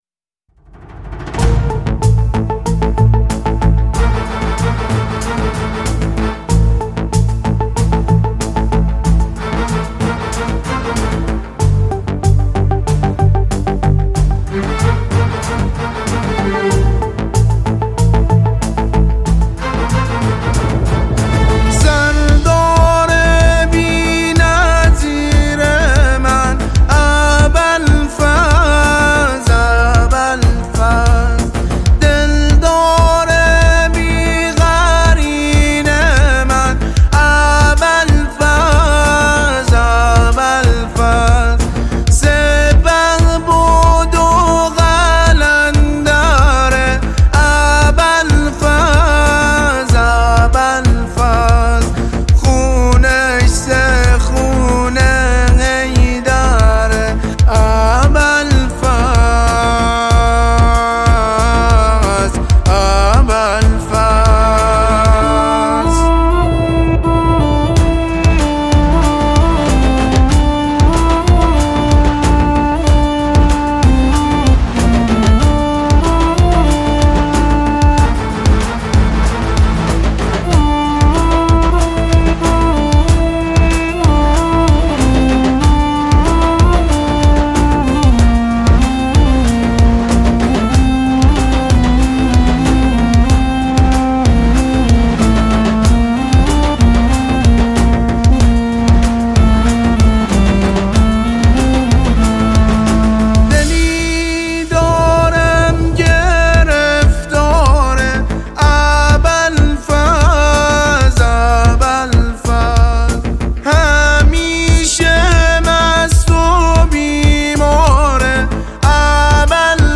مداحی جدید ویژه محرم استدیویی(ابوالفضل)